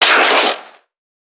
NPC_Creatures_Vocalisations_Infected [68].wav